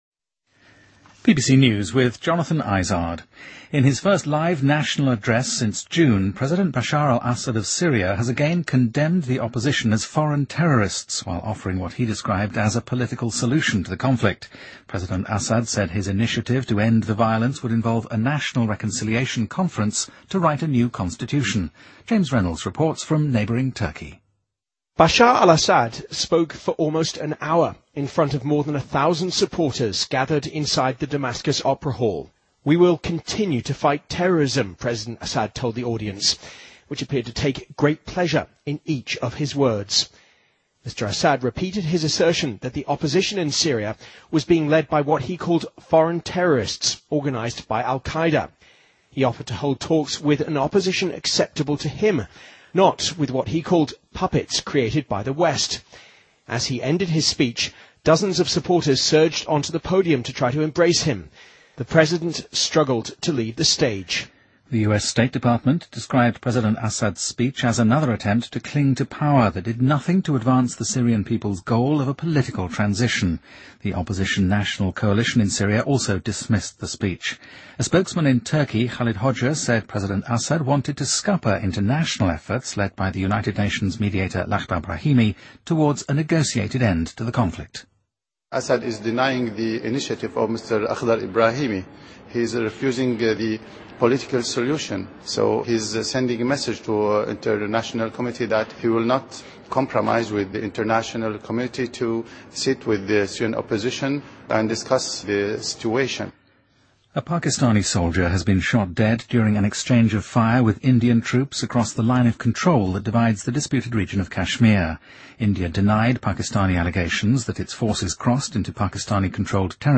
Date:2013-01-07Source:BBC Editor:BBC News